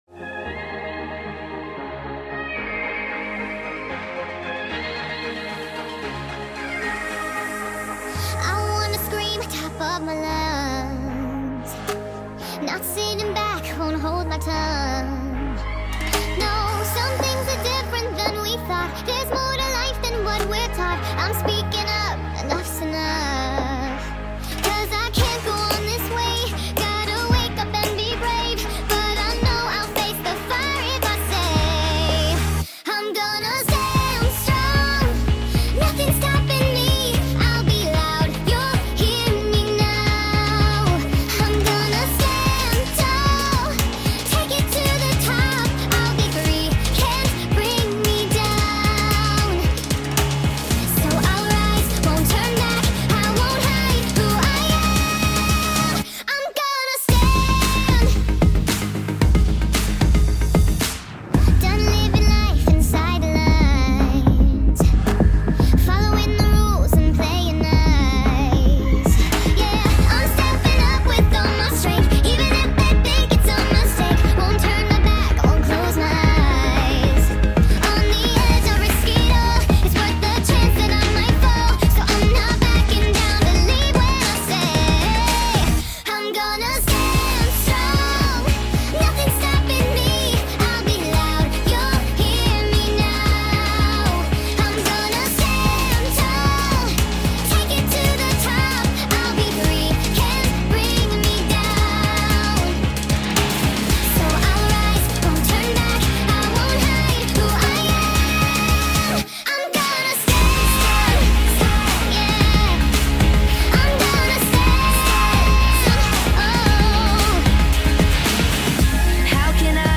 sped up songs